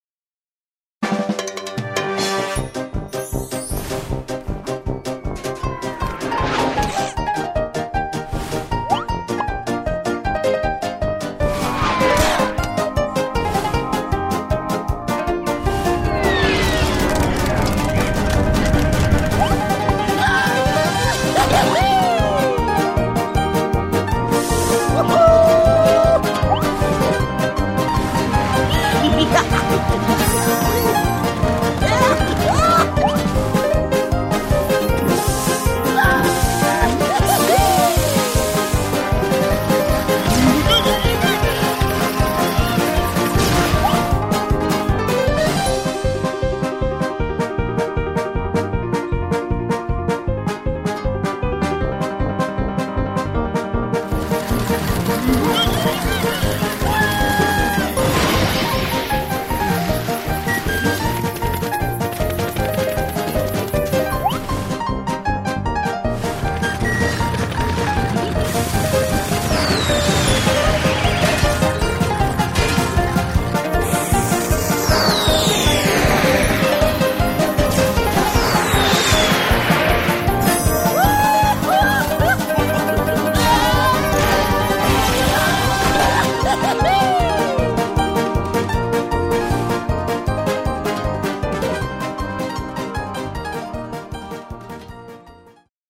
Категория: Рингтоны из игр